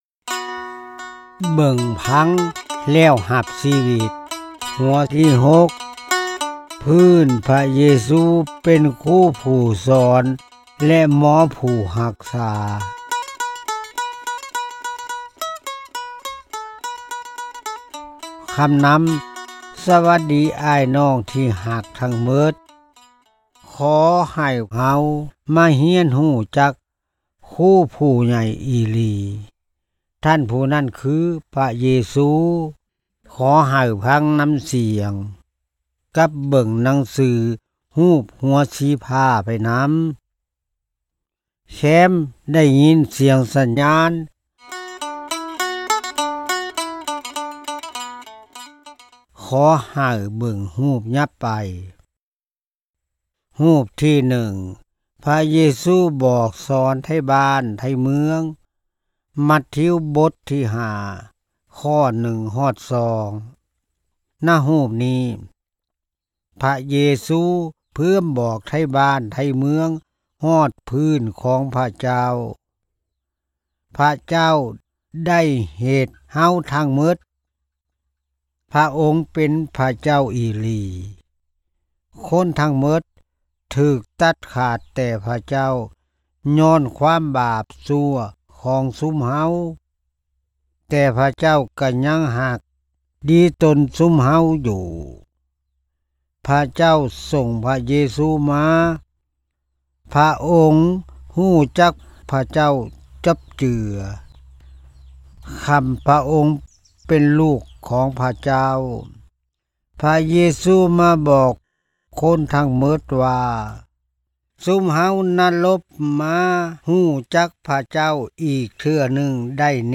Listen as one of our field recordists tells the amazing story of how God used her and her husband’s work to bring villagers to Christ: